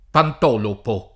DOP: Dizionario di Ortografia e Pronunzia della lingua italiana
[ pant 0 lopo ]